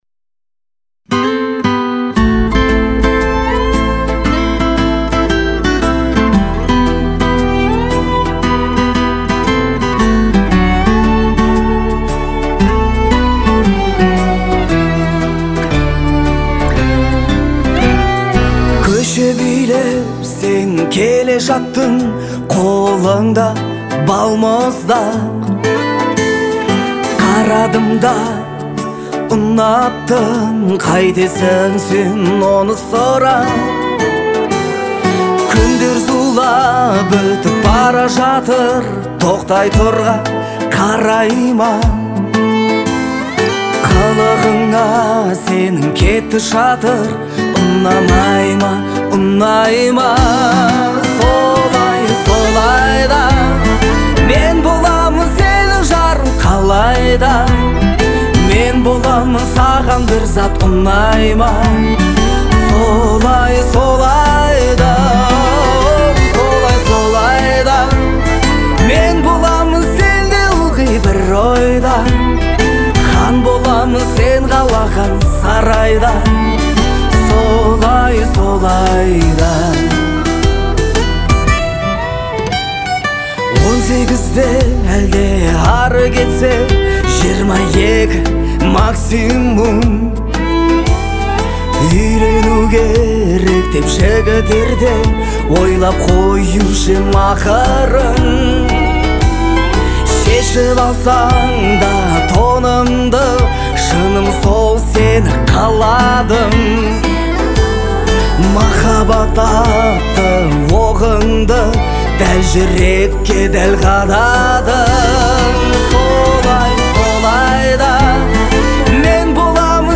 это яркая и мелодичная песня